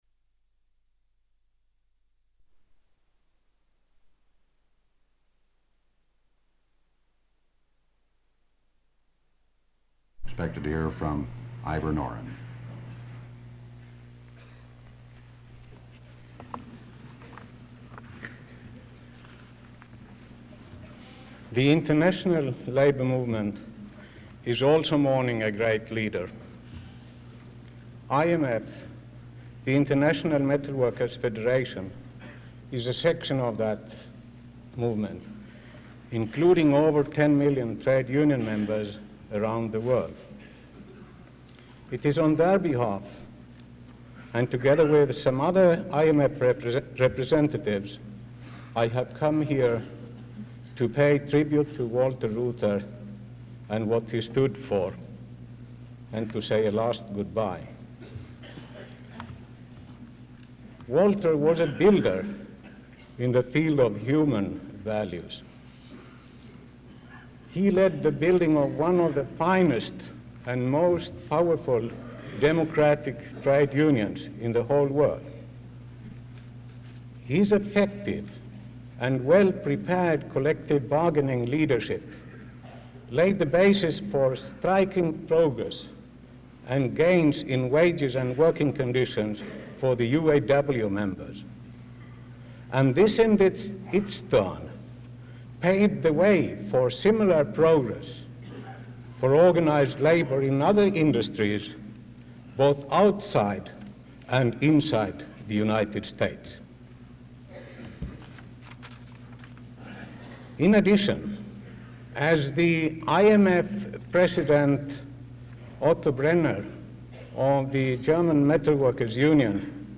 Walter P. and May Reuther Memorial Services, Reel 3, Detroit, MI